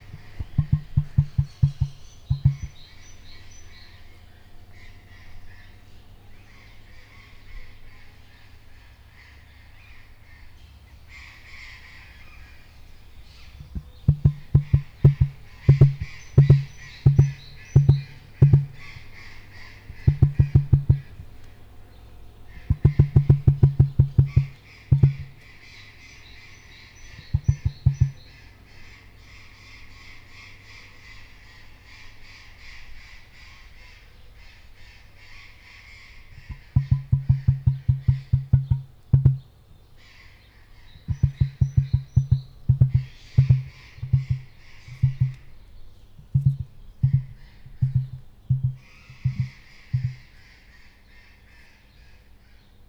egyenleteshangerovel_marantzpuska00.52.WAV